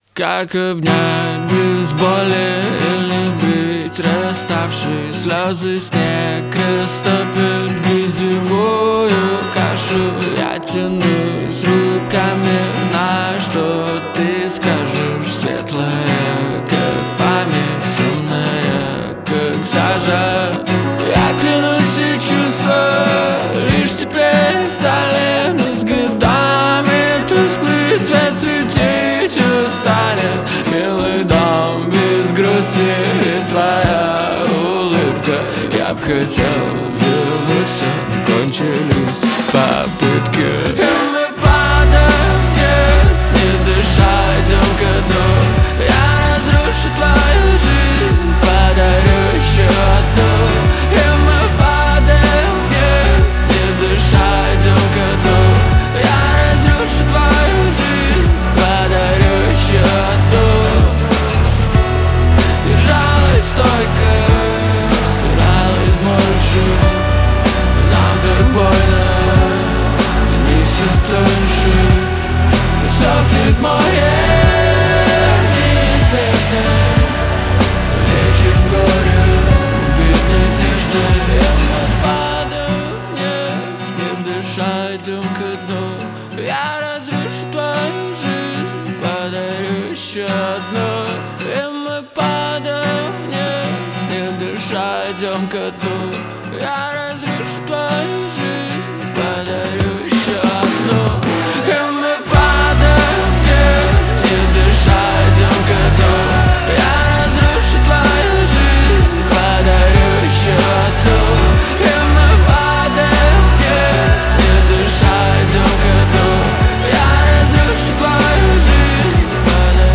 Жанр Поп.